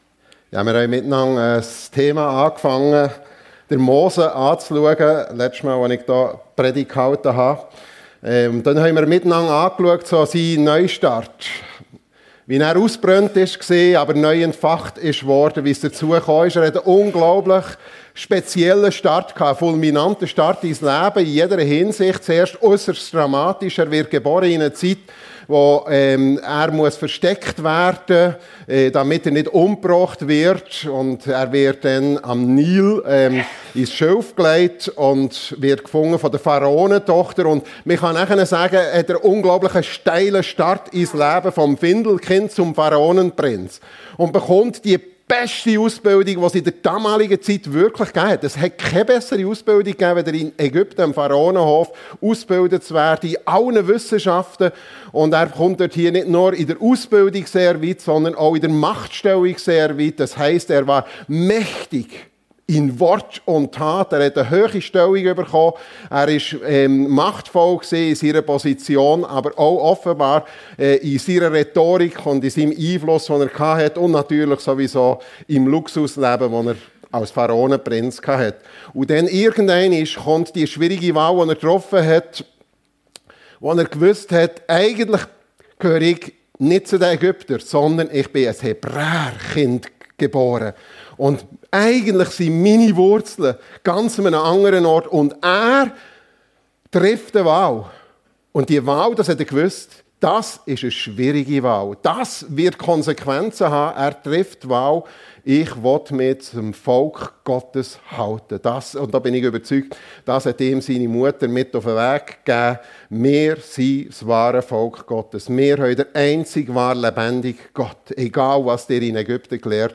Mose: Startschwierigkeiten - eine harzige Berufung ~ FEG Sumiswald - Predigten Podcast